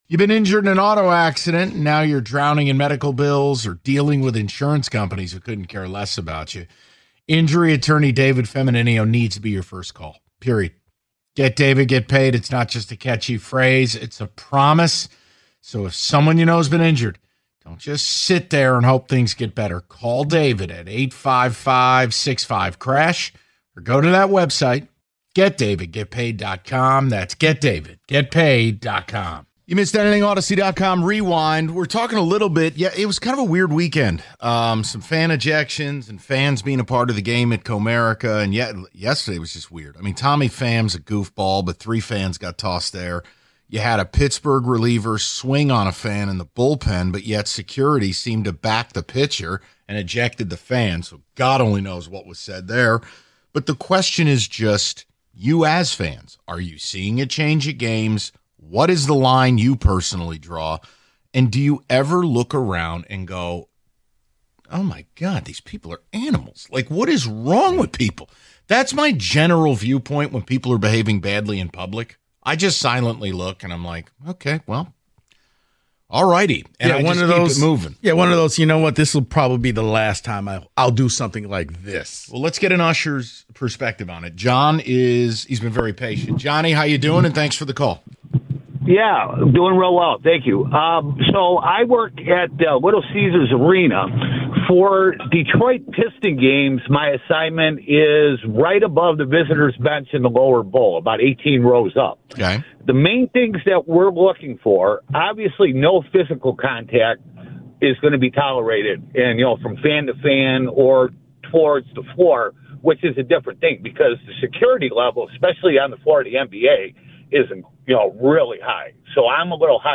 A LCA Usher Chimes In On Sports Fan Behavior The Valenti Show Audacy Sports 3.8 • 1.1K Ratings 🗓 20 June 2025 ⏱ 11 minutes 🔗 Recording | iTunes | RSS 🧾 Download transcript Summary The guys take a call from an usher at Little Caesars Arena, who gives some unique perspective on fan behavior.